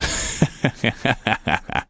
00 - postal - He he he ha ha ha ha